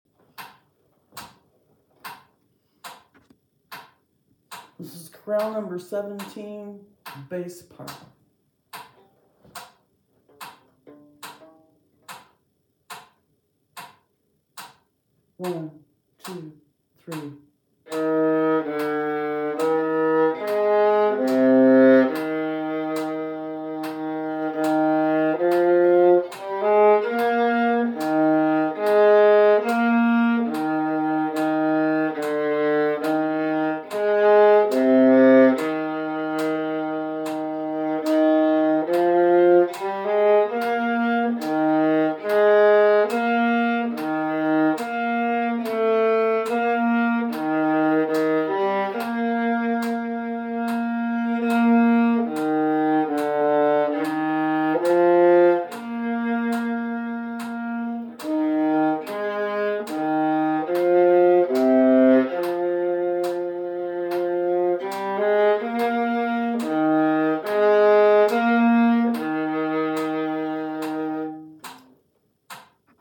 Chorale No. 17, Bass